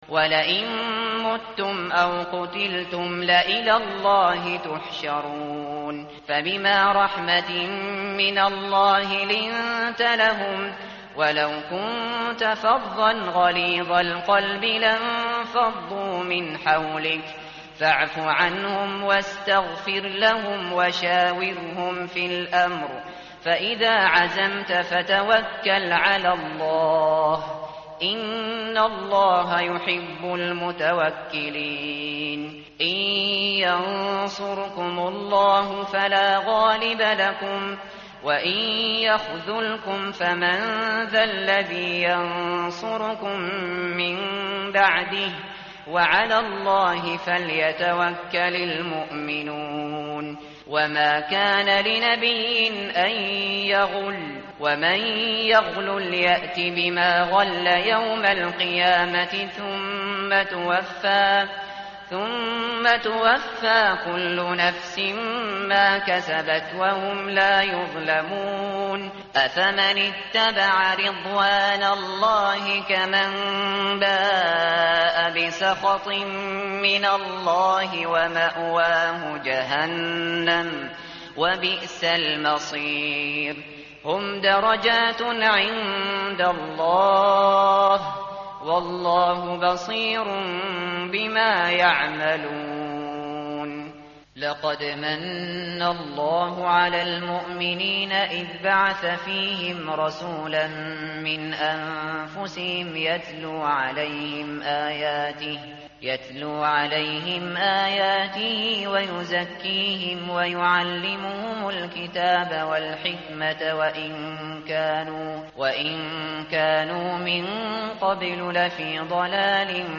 tartil_shateri_page_071.mp3